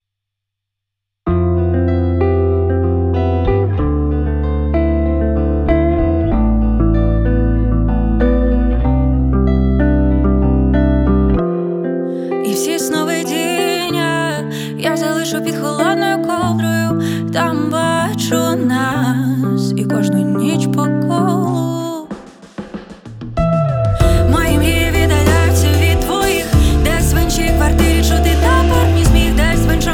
Жанр: Поп музыка / Рок / Украинские